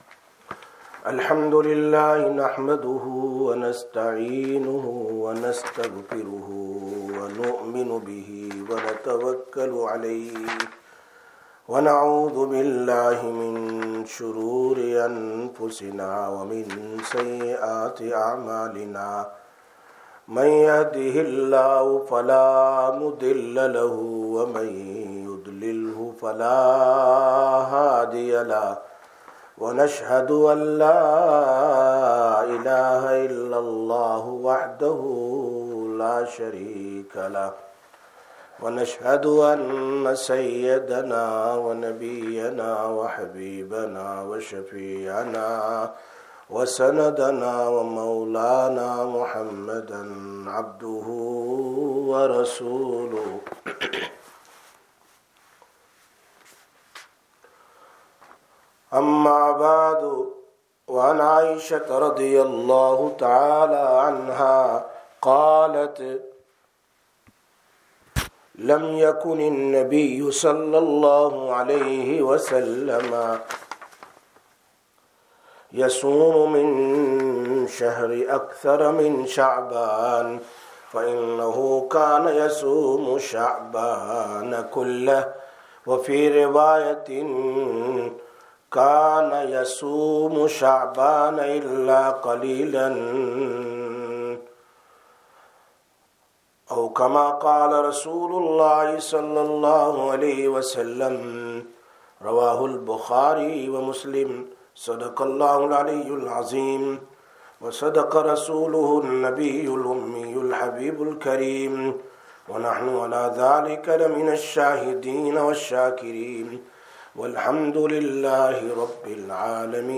24/03/2021 Sisters Bayan, Masjid Quba